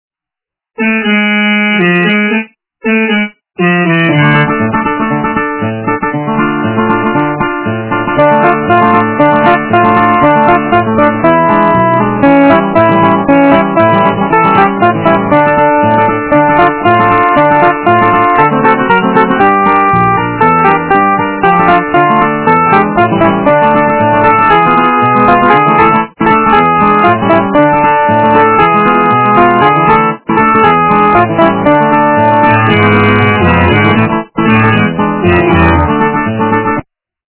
качество понижено и присутствуют гудки.
полифоническую мелодию